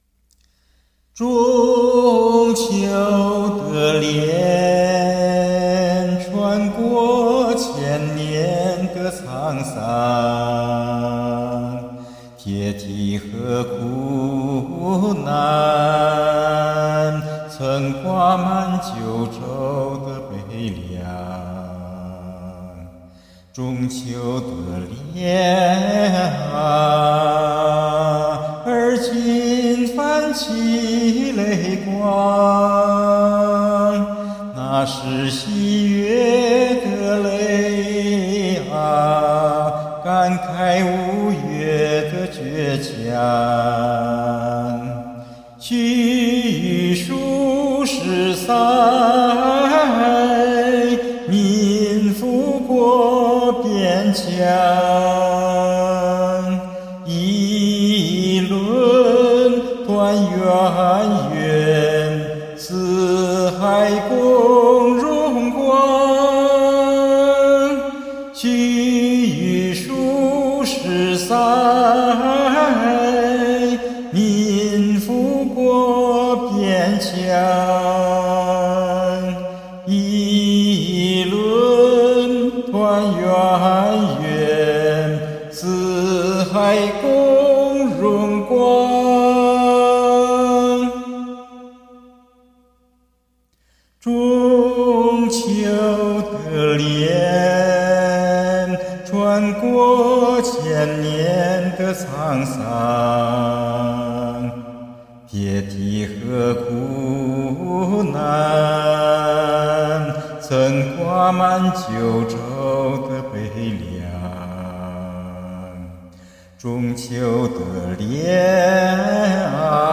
曲调有民族风。